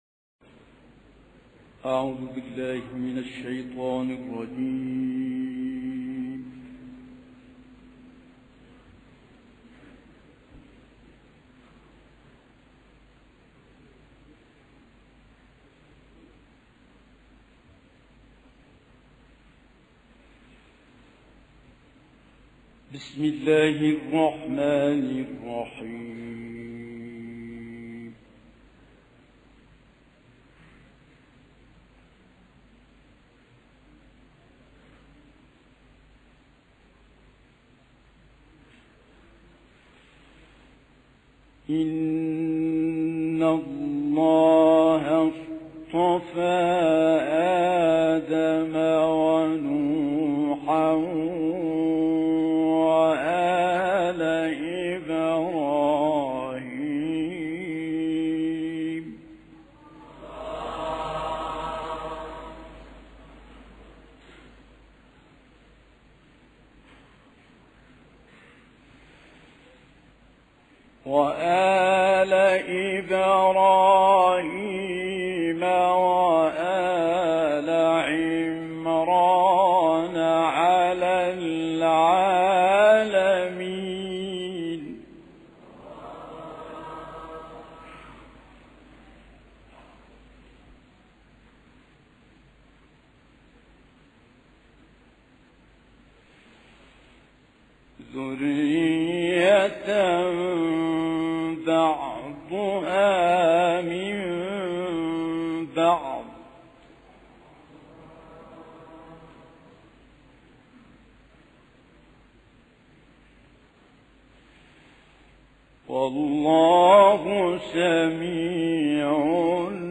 القارئ السيد متولي عبد العال -سورة آل عمران.